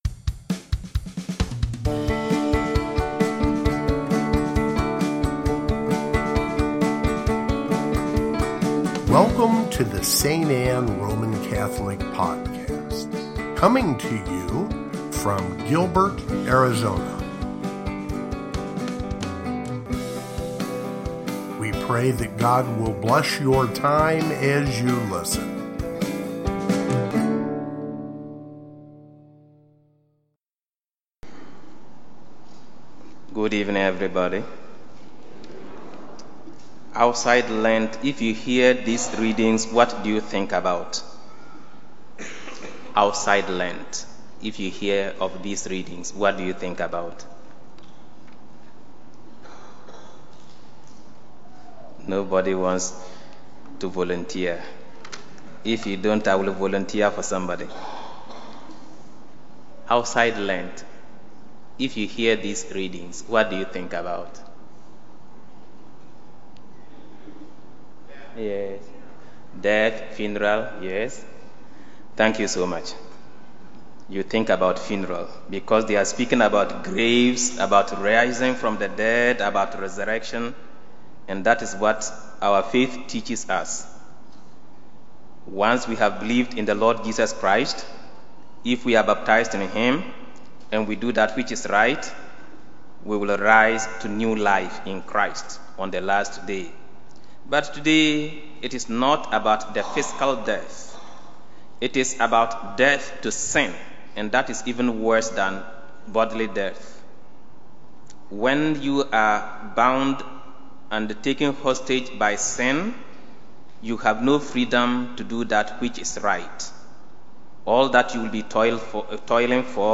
Fifth Sunday of Lent (Homily) | St. Anne